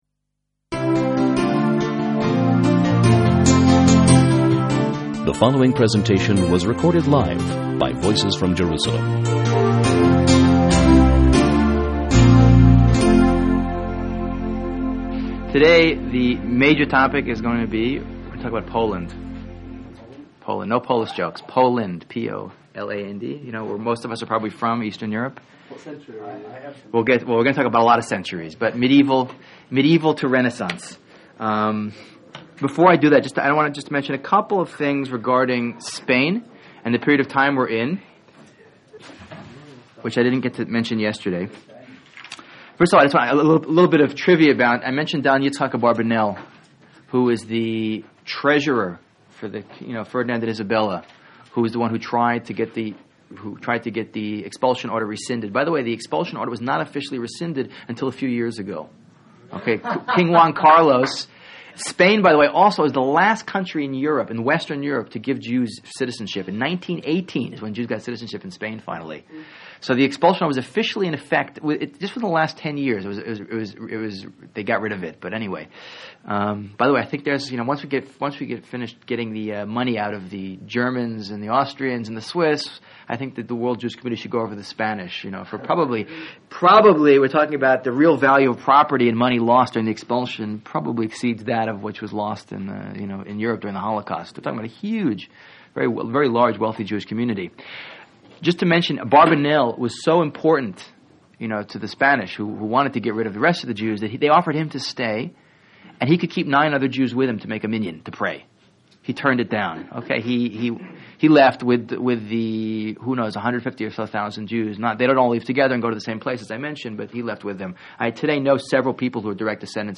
Outline or Thoughts on this Lecture Commenting is not available in this channel entry.